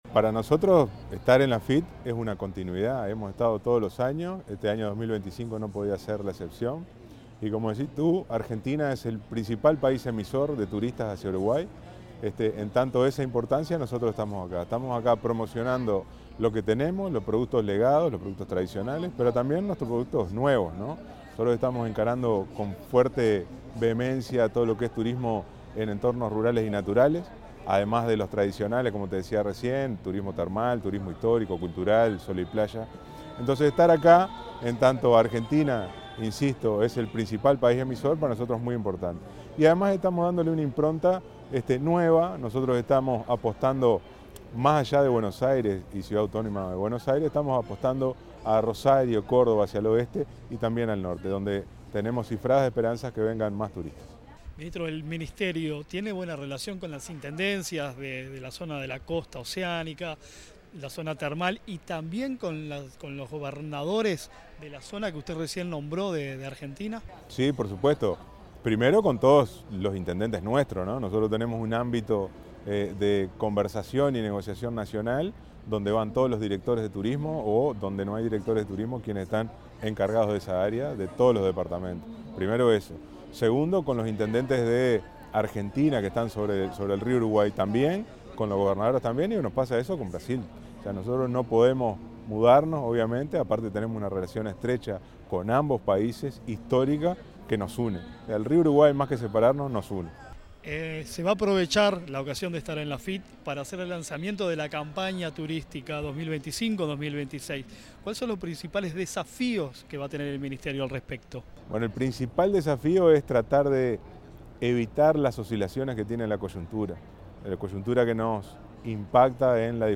Palabras del ministro de Turismo, Pablo Menoni
El ministro de Turismo, Pablo Menoni, se expresó durante el lanzamiento de la campaña “Uruguay sorprende”, en la Feria Internacional de Turismo, en